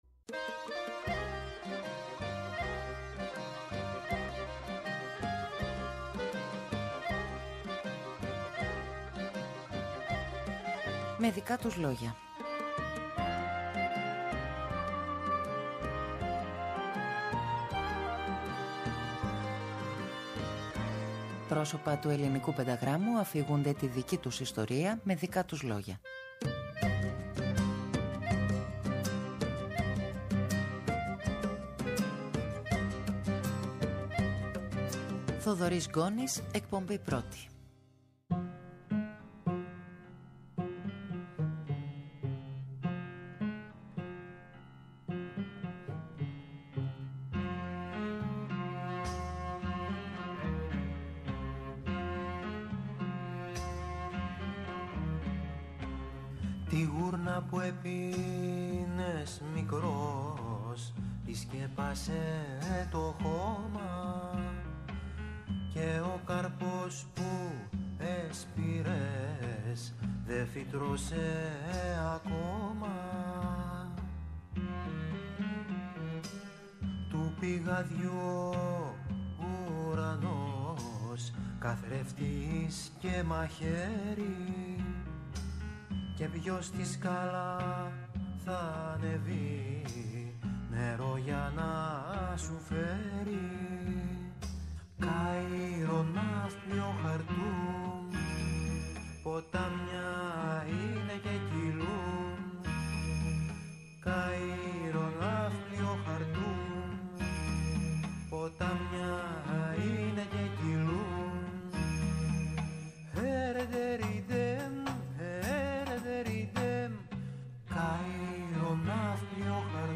Πρόσωπα του πενταγράμμου αφηγούνται τη δική τους ιστορία…